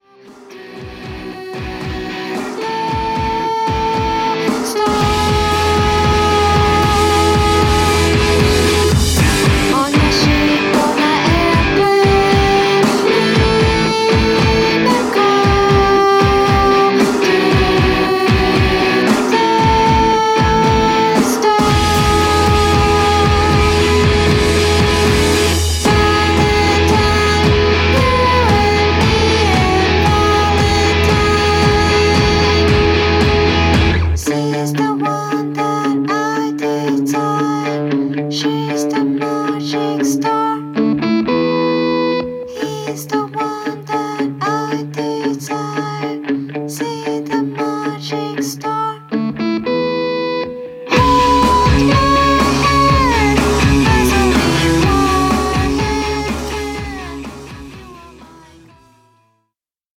破天荒でありながらどこかポップな独自のロックサウンドが炸裂した1枚です。